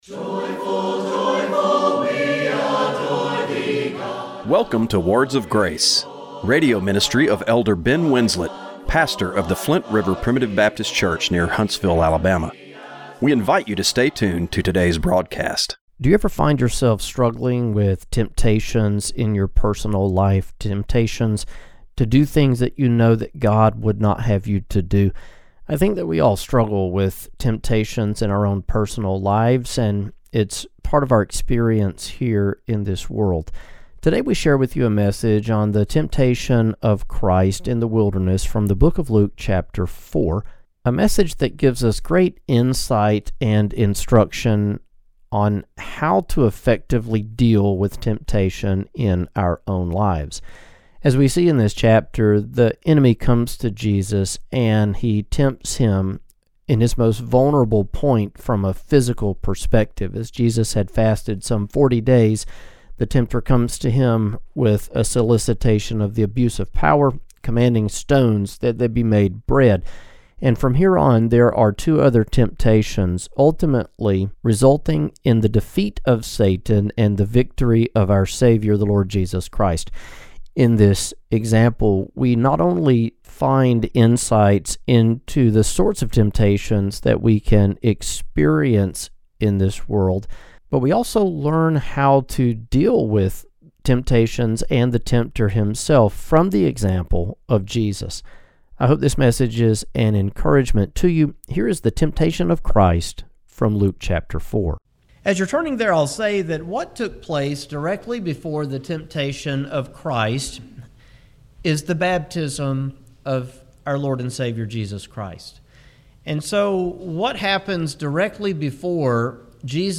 Radio broadcast for September 28, 2025.